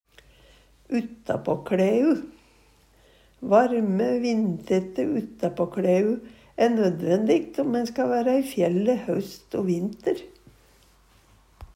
uttapåkLæu ytterklede, klede til å ha utanpå andre klede Eksempel på bruk Varme, vindtette uttapåkLæu æ nødvændigt om ein ska væra i fjælle haust o vinter. Høyr på uttala Ordklasse: Uttrykk Kategori: Personleg utstyr, klede, sko Attende til søk